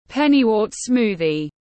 Sinh tố rau má tiếng anh gọi là pennywort smoothie, phiên âm tiếng anh đọc là /ˈpen.i.wɜːt ˈsmuː.ði/
Pennywort smoothie /ˈpen.i.wɜːt ˈsmuː.ði/